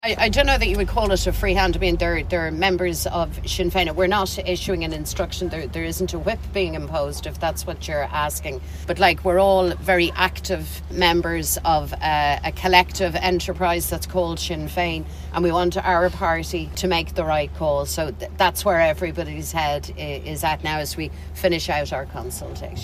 Speaking at the Sinn Fein think-in in Dún Laoghaire, Mary Lou McDonald said the party whip will not be imposed.